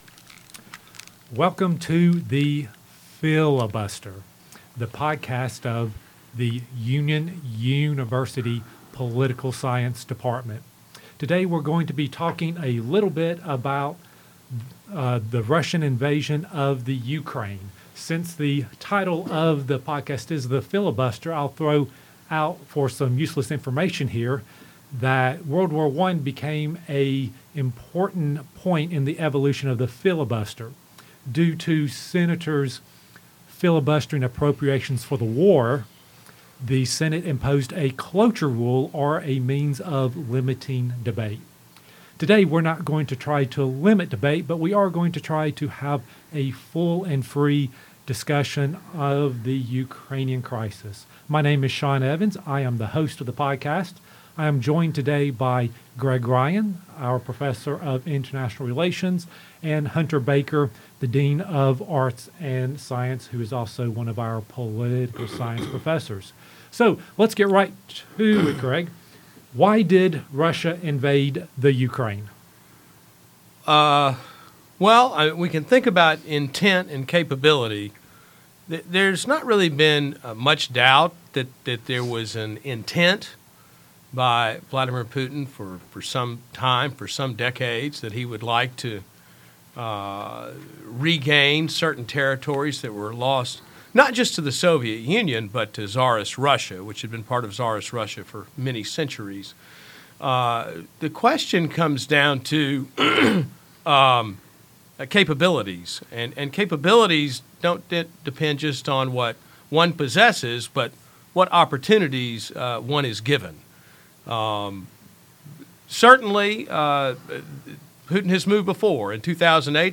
The Political Science Faculty discuss why Russia invaded the Ukraine, what can be done about it, and how the invasion impacts global and American politics.